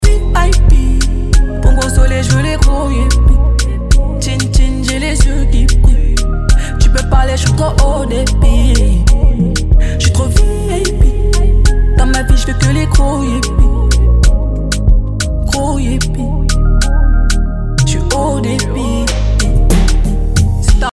• Качество: 320, Stereo
спокойные
соул
Жанр: RnB/Soul